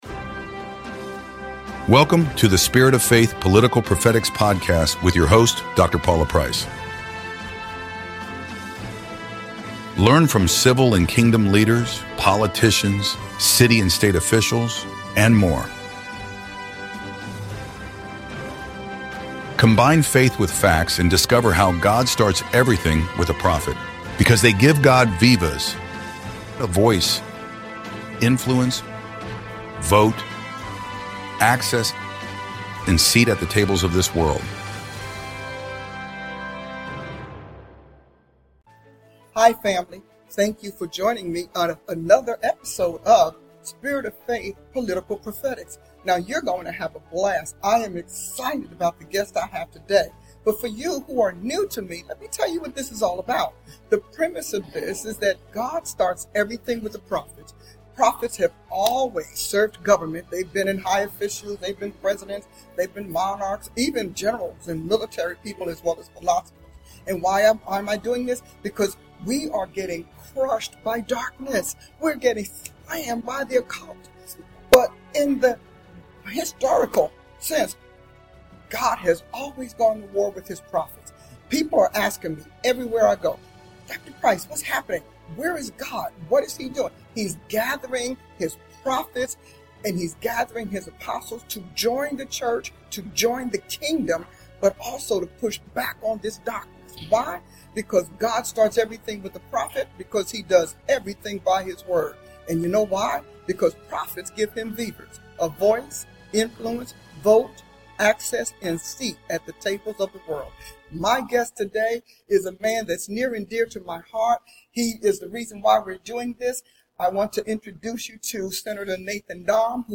Today's conversation is with Oklahoma GOP State Senator Nathan Dahm.